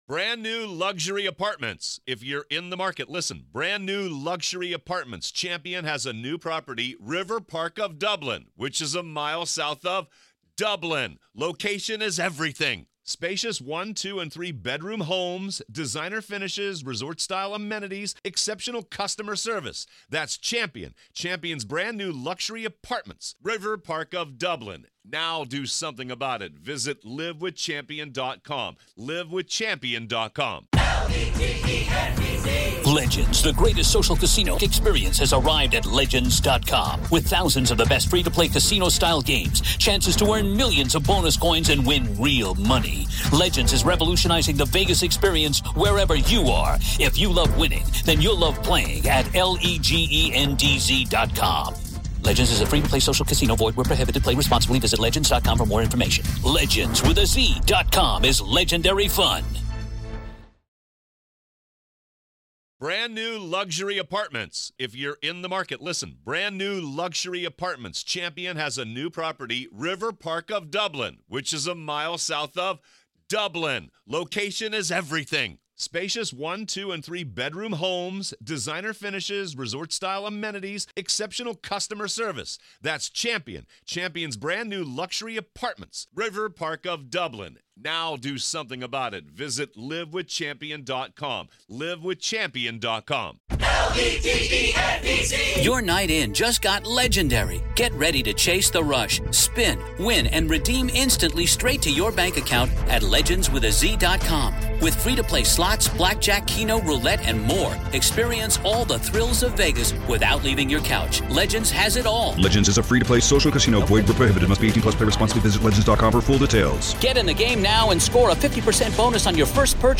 In this conversation, we explore how this case has exposed systemic failures—from weak bail policies to baffling prosecutorial decisions. We look at Arkansas’s stand-your-ground and defense-of-others laws, and why they should apply.